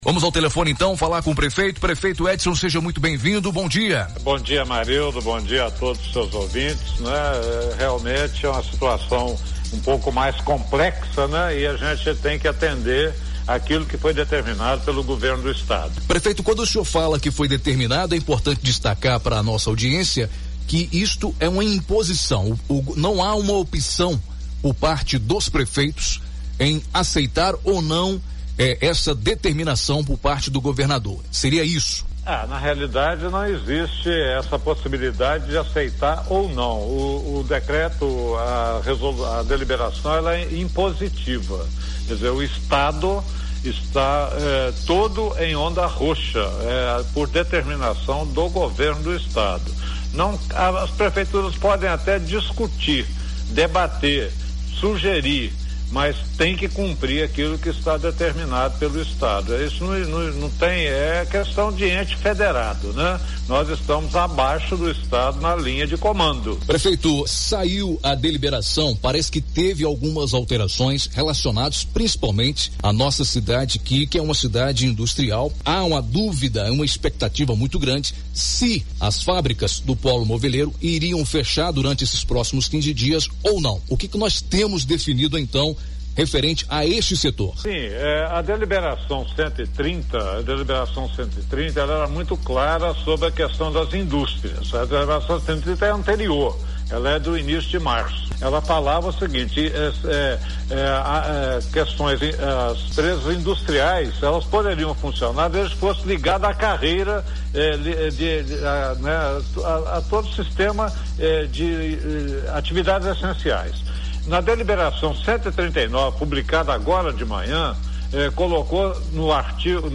entrevista-prefeito-edson-sobre-decreto-onda-roxa-este.mp3